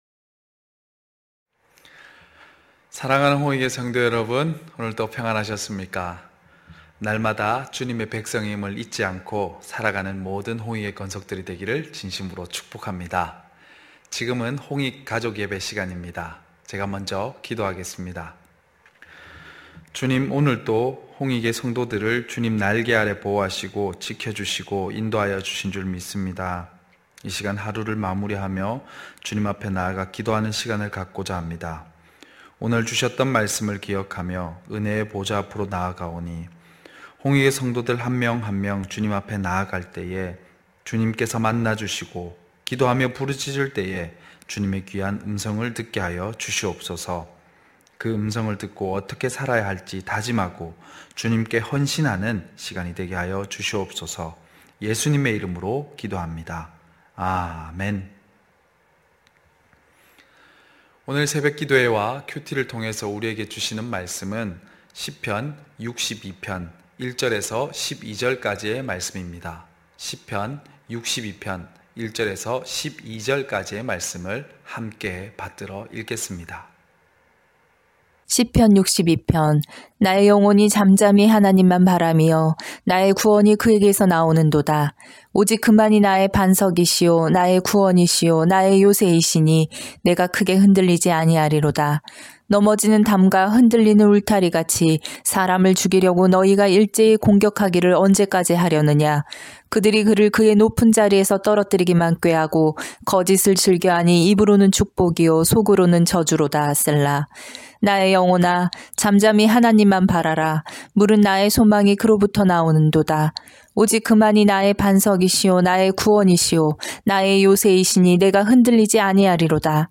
9시홍익가족예배(3월12일).mp3